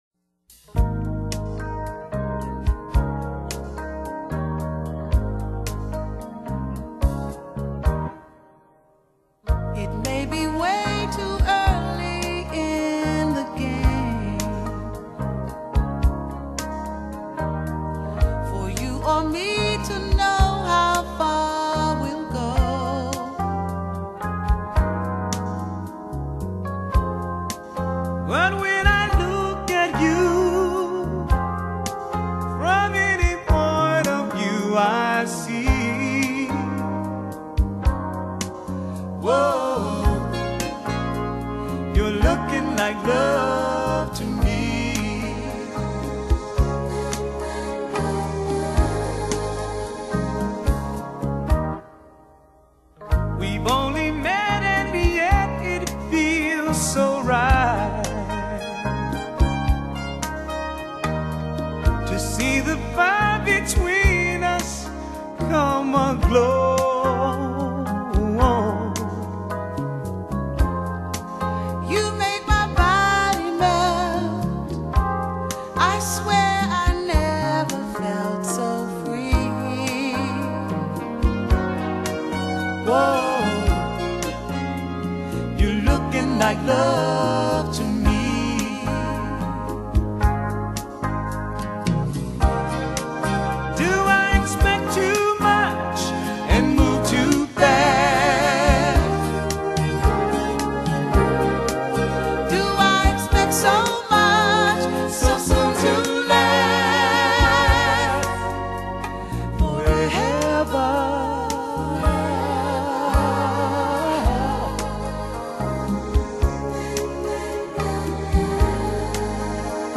這張是他們倆對唱的專輯